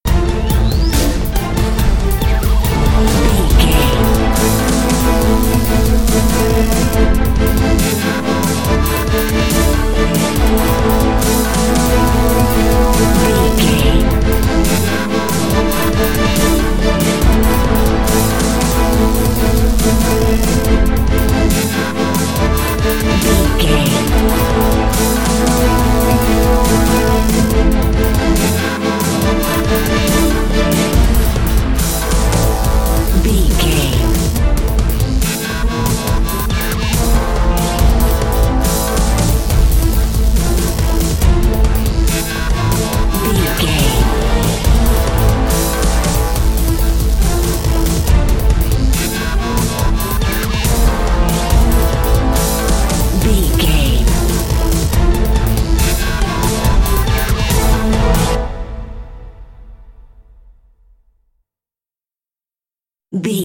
Fast paced
In-crescendo
Aeolian/Minor
strings
drums
orchestral
orchestral hybrid
dubstep
aggressive
energetic
intense
bass
synth effects
wobbles
driving drum beat
epic